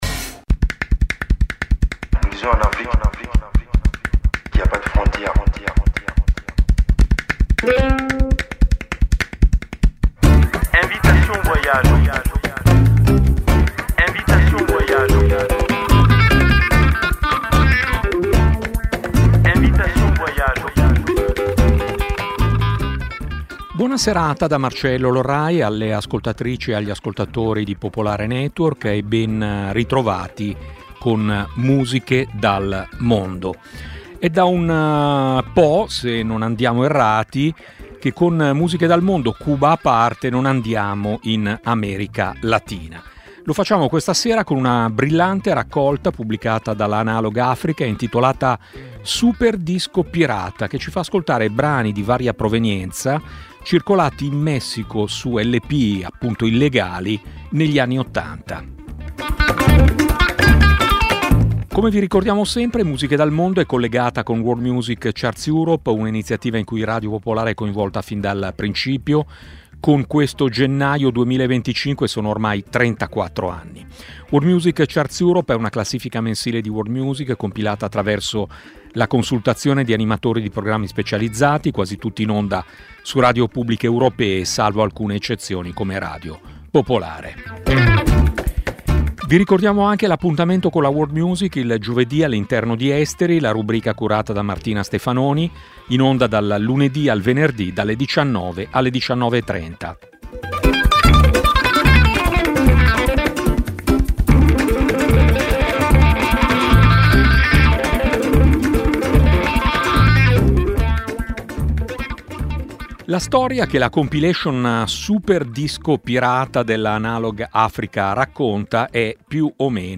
La trasmissione propone musica che difficilmente le radio mainstream fanno ascoltare e di cui i media correntemente non si occupano. Un'ampia varietà musicale, dalle fanfare macedoni al canto siberiano, promuovendo la biodiversità musicale.